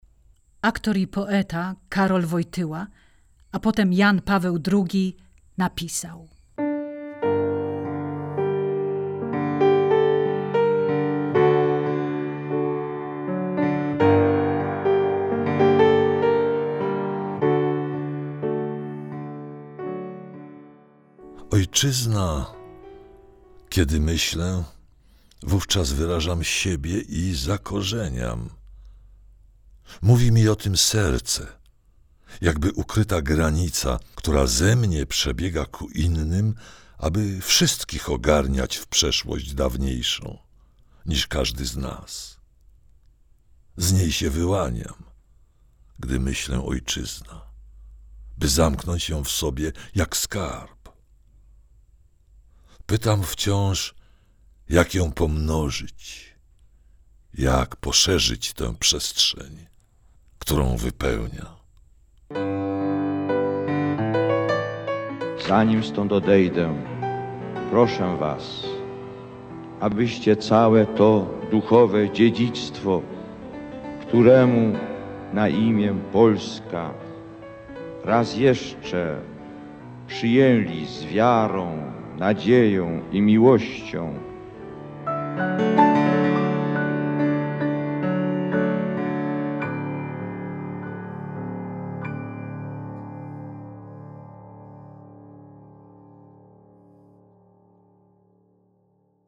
Usłyszymy też głos Papieża Jana Pawła II
Wykonawcy Scenariusz, oprawa muzyczna, efekty i realizacja projektu
Recytacje